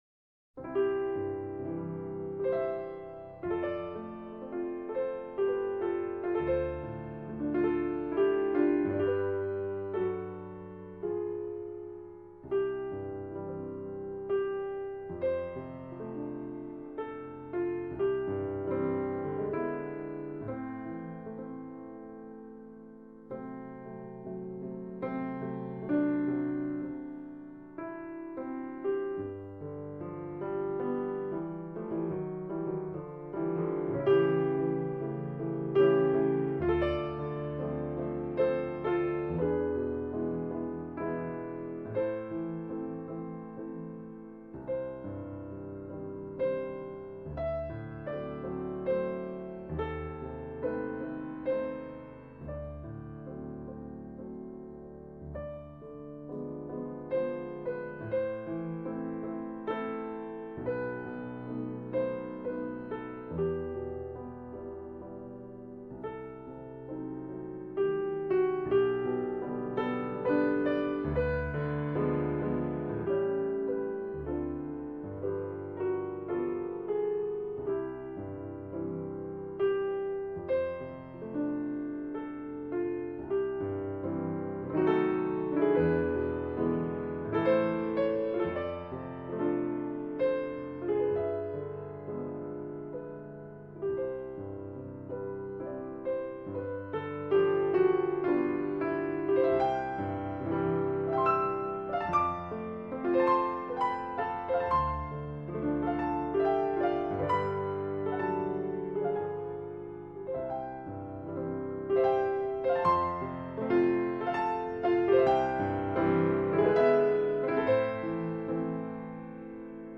คำสำคัญ : เปียโน, ลมหนาว, เพลงพระราชนิพนธ์